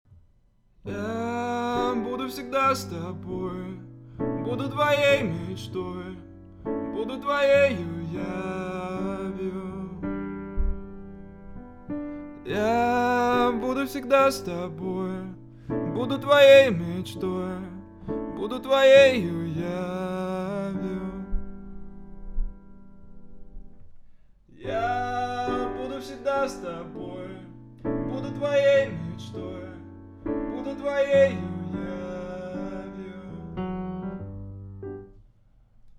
Пс вот результат перемещения микрофона в пространстве - ~50см, ~20см, ~метр. (вся обработка выключена и ревер тоже не забыл выключить) Вложения 1.mp3 1.mp3 1,4 MB · Просмотры: 644